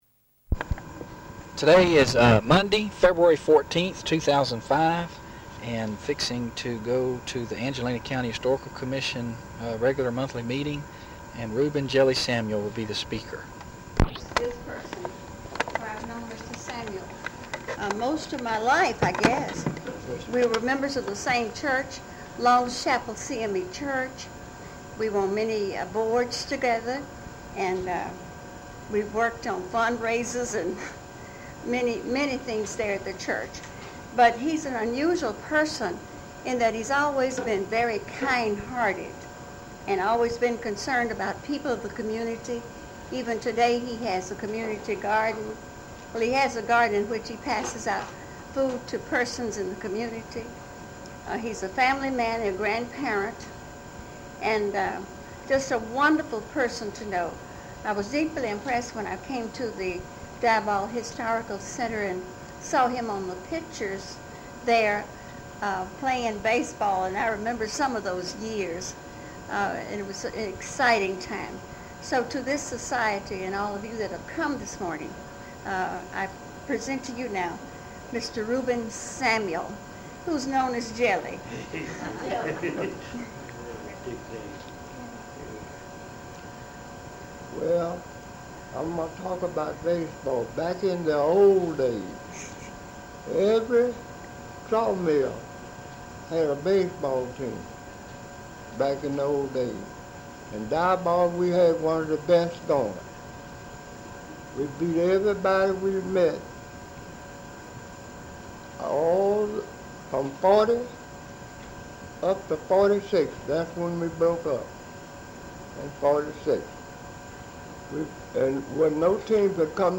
Interview 160b